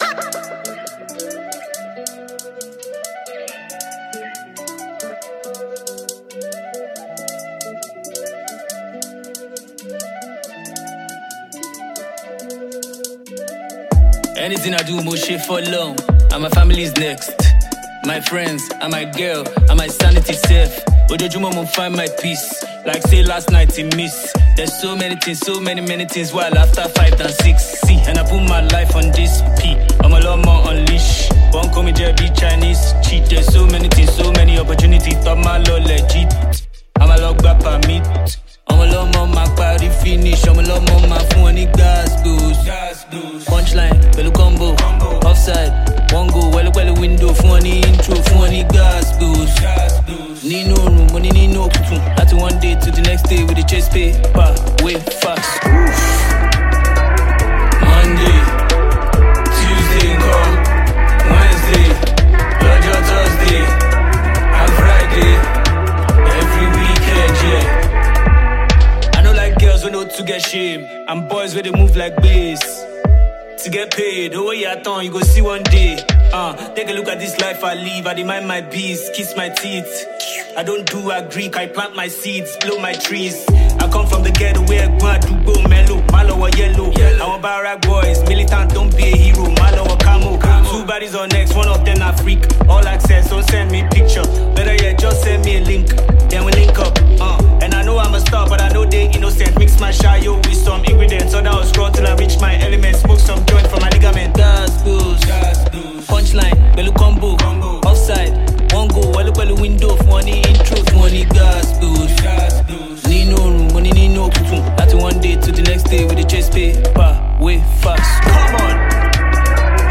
Nigerian rapper and reality TV star